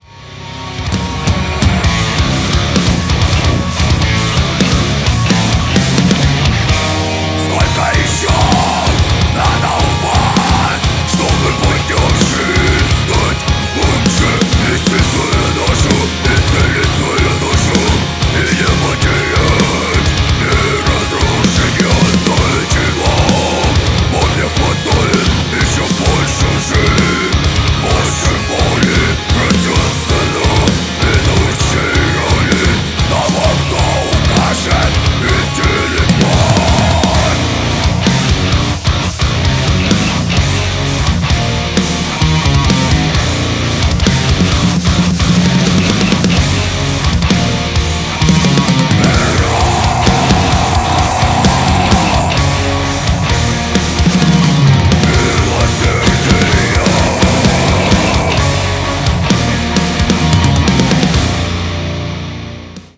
����� djent-���������